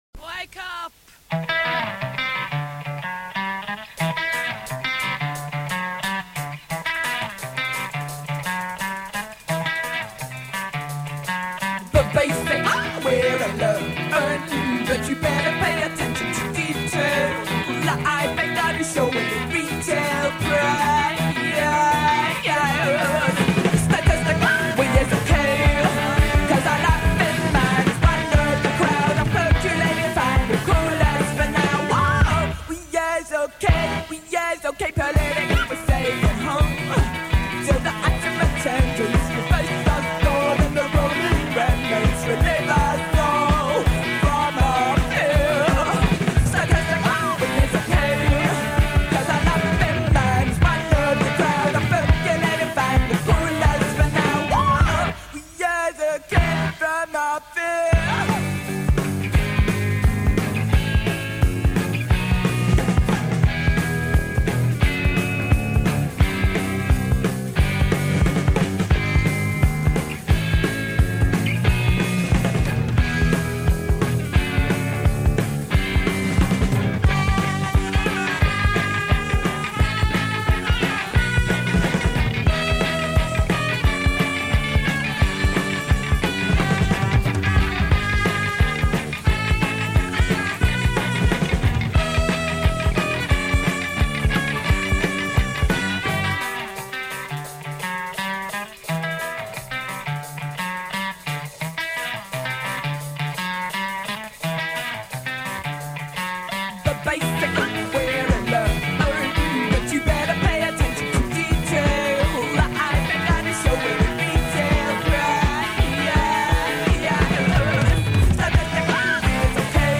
saxophonist
Labeled a Post-Punk band